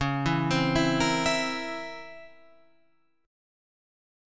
DbmM13 Chord
Listen to DbmM13 strummed